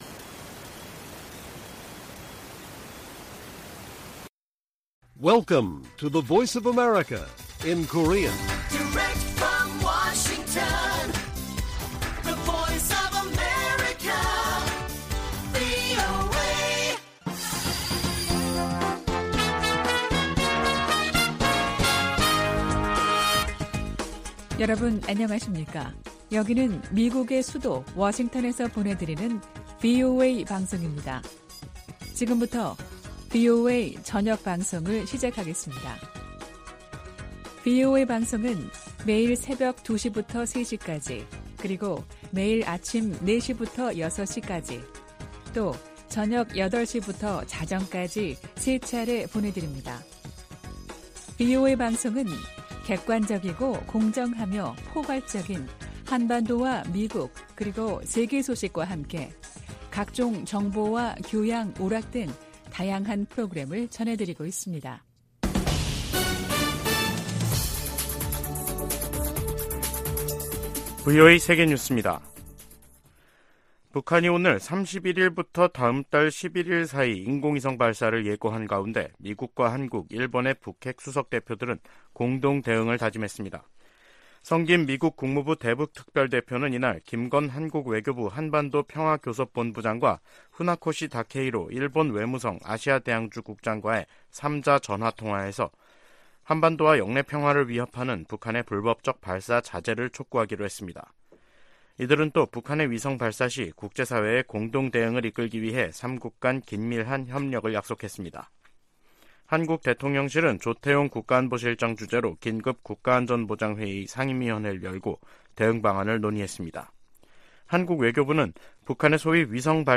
VOA 한국어 간판 뉴스 프로그램 '뉴스 투데이', 2023년 5월 29일 1부 방송입니다. 북한이 군사정찰위성 1호기 발사 계획을 공개했습니다.